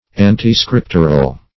Search Result for " antiscriptural" : The Collaborative International Dictionary of English v.0.48: Antiscriptural \An`ti*scrip"tur*al\, a. Opposed to, or not in accordance with, the Holy Scriptures.
antiscriptural.mp3